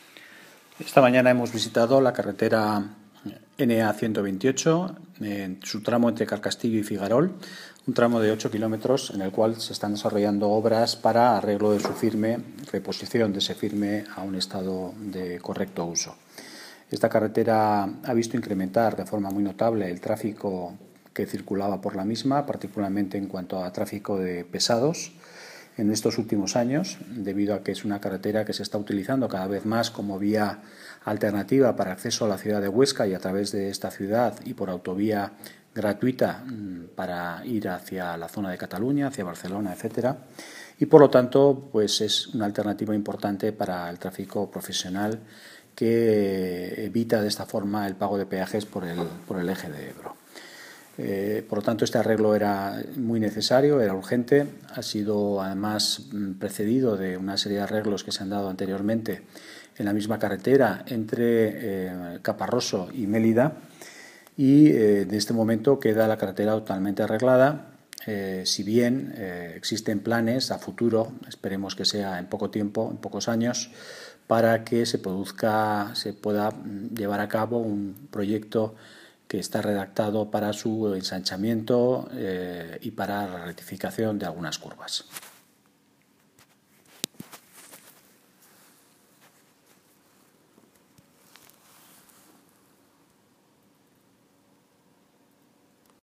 Declaraciones del consejero de Fomento, Luis Zarraluqui